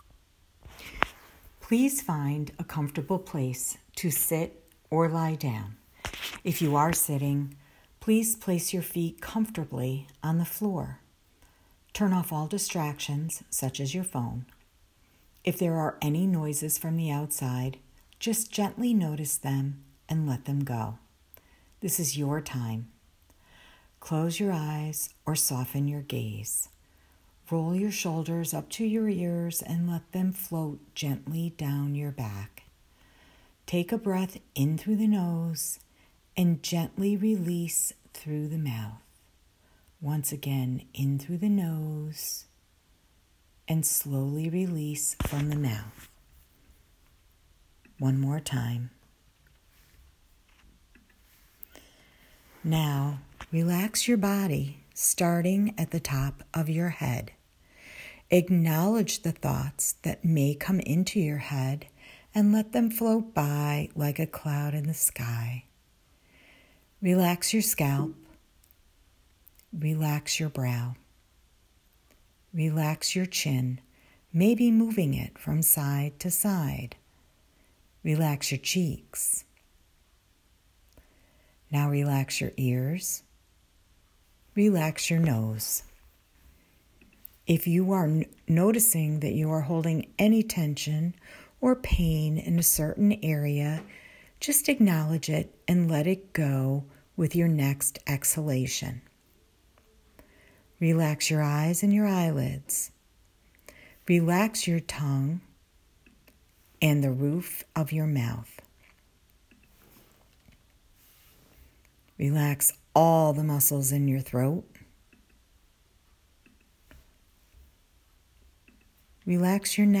Guided-Imagery-meditation.wav